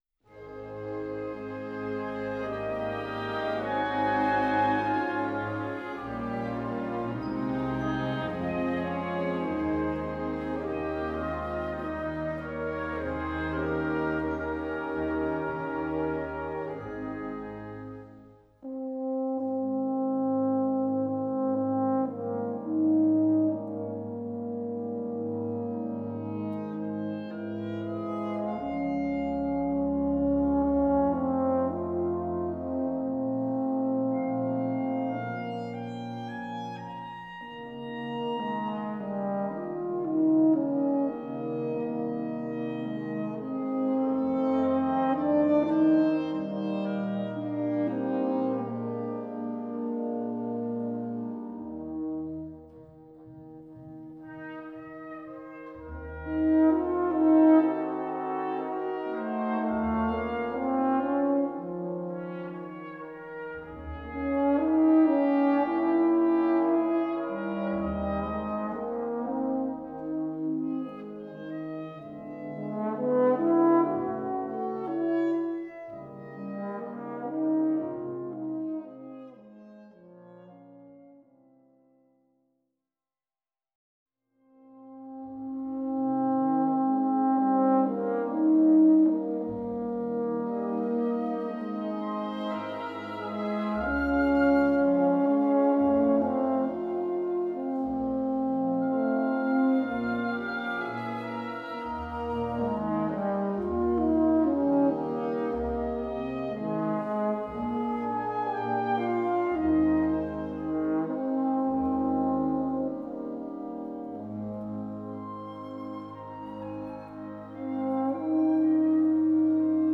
Gattung: Solo für Euphonium und Blasorchester
Besetzung: Blasorchester
das Euphonium als Soloinstrument